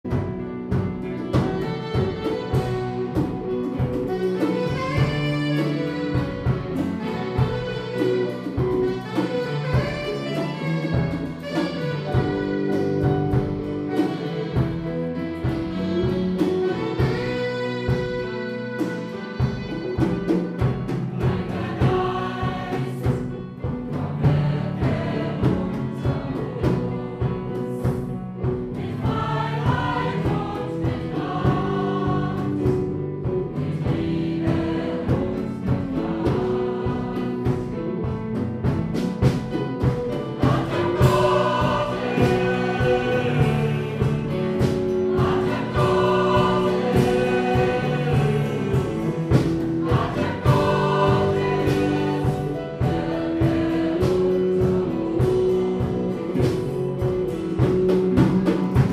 Wir sind ein rein live-orientiertes Ensemble und haben (noch) keine Studioaufnahmen eingespielt. Mit einfachen Mitteln haben wir einige unserer Auftritte mitgeschnitten.